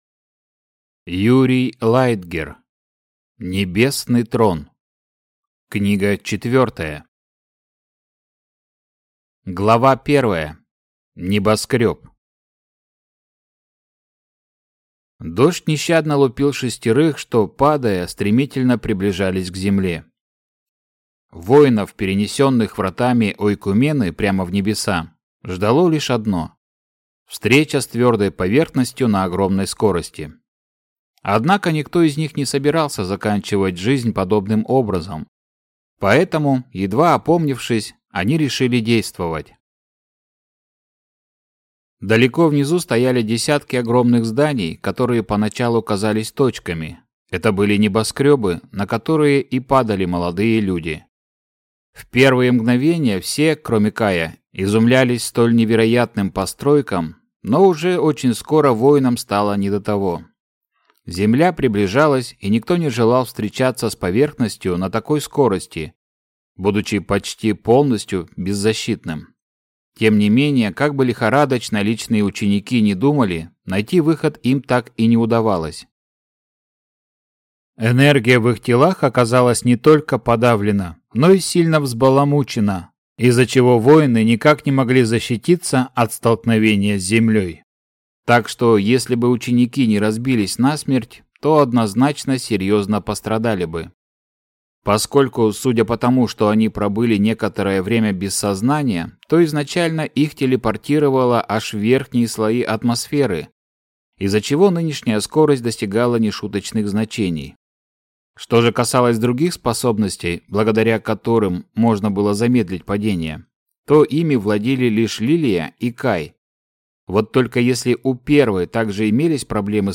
Аудиокнига Небесный Трон. Книга 4 | Библиотека аудиокниг